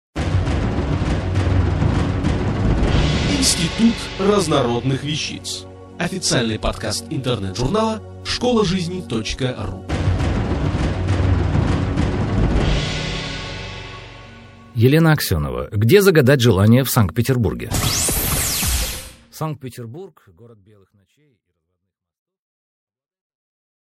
Аудиокнига Где загадать желание в Санкт-Петербурге?